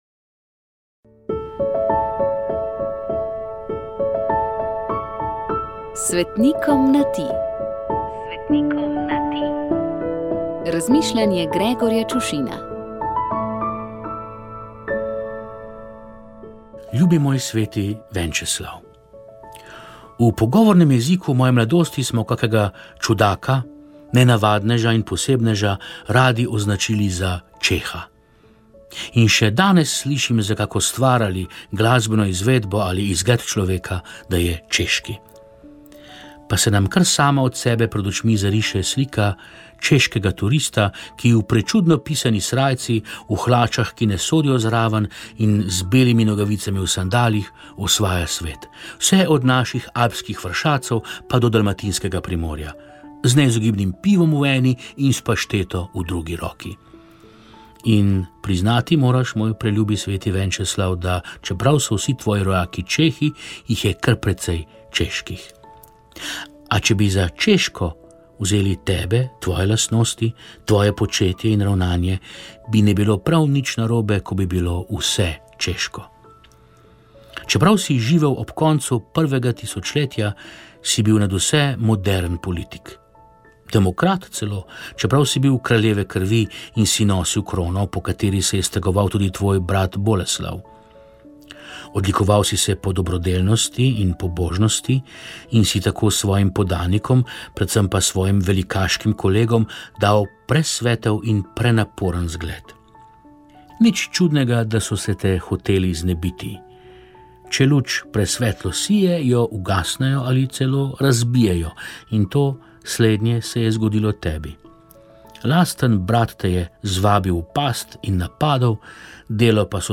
Sogovorniki so bili veliki sarajevski mufti Nedžad Grabus, nadškof Marjan Turnšek in nekdanji direktor urada za verske skupnosti Drago Čepar.